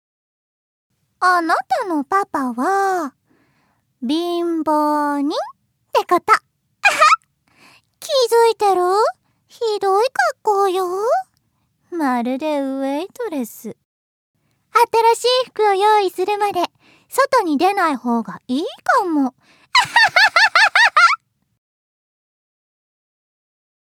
◆いじわるな女の子◆